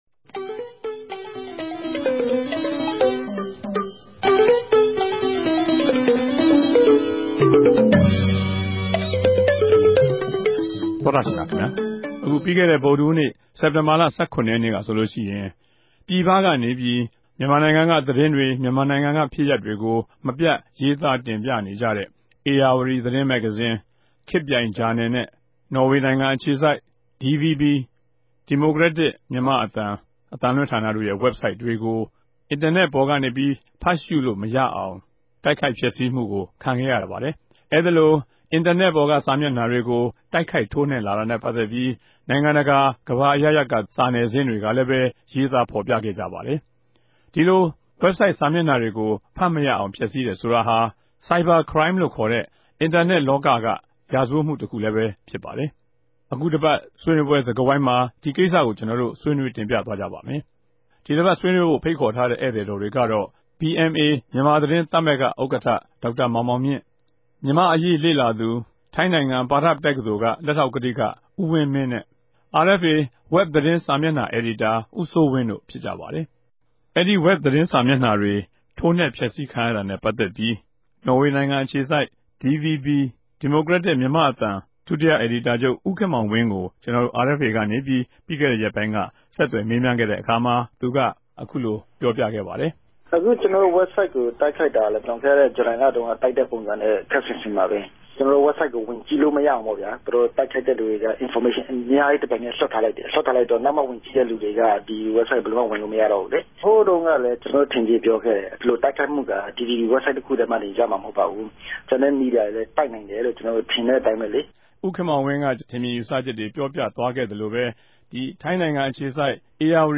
အာအက်ဖ်အေ တနဂဿေိံြ စကားဝိုင်း အစီအစဉ်မြာ ္ဘပီးခဲ့တဲ့ ရက်ပိုင်းအတြင်းက ူမန်မာ့ ဒီမိုကရေစီရေး အားပေးတဲ့ အင်တာနက် ဝက်ဘ် စာမဵကိံြာ သုံးခု ဖတ်ရြ မရအောင် ဖဵက်ဆီးခံရတဲ့ ကိင်္စကို အေူခူပ္ဘြပီး ဆြေးေိံြးထားုကပၝတယ်၊၊